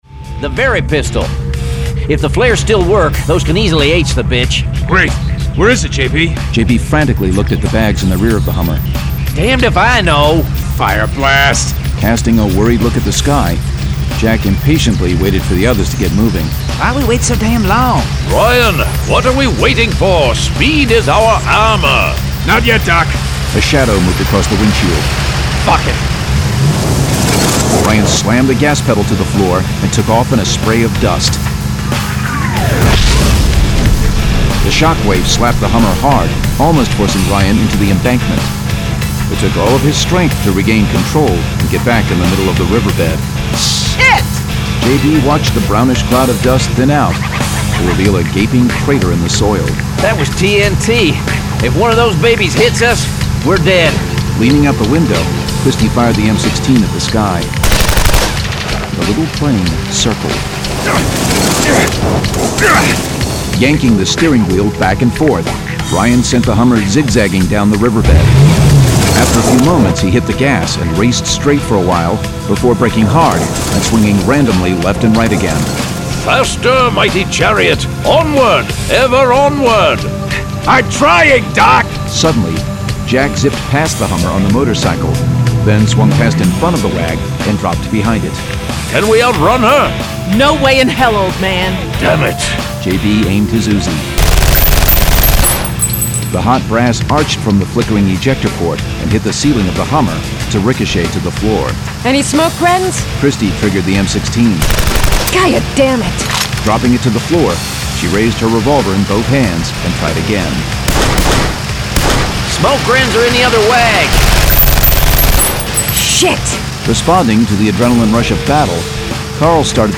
Full Cast. Cinematic Music. Sound Effects.
[Dramatized Adaptation]